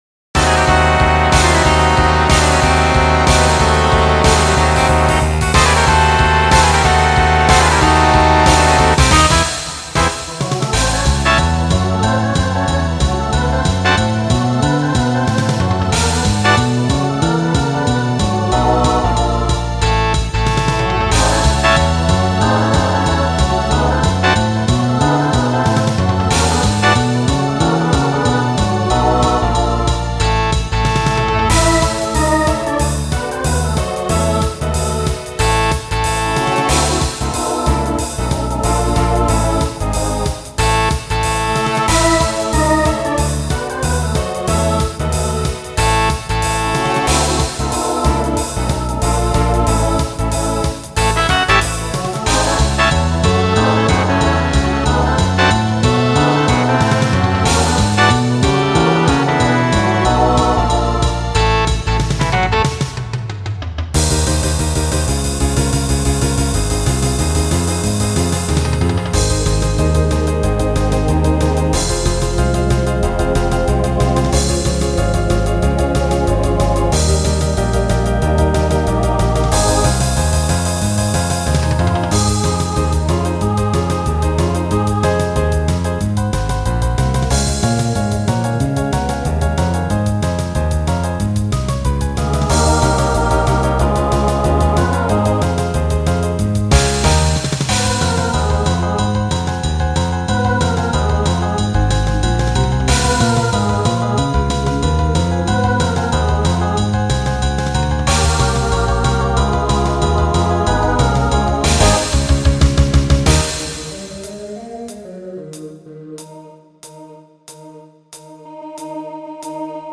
Voicing SATB Instrumental combo Genre Rock , Swing/Jazz